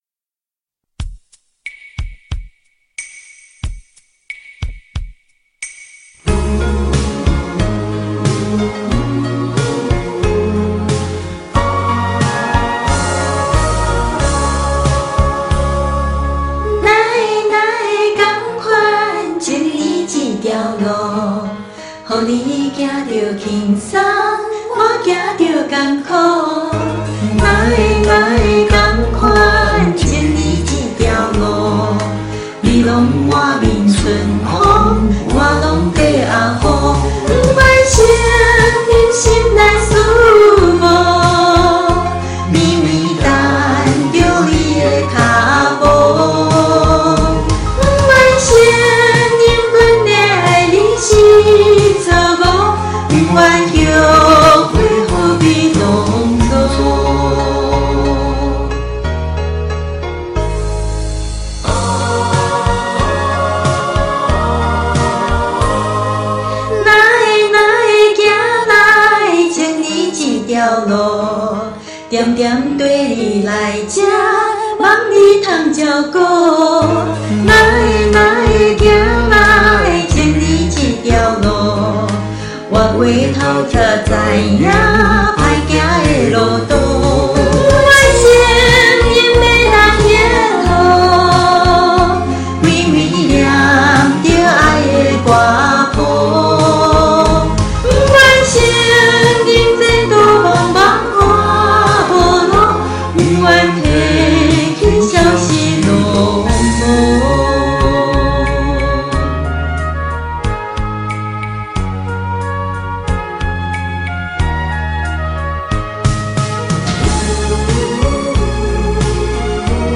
是的，姐姐嚐試唱閩南歌好讓父母以及高齡的外婆聽。
閩南語？聽著別有韻味~~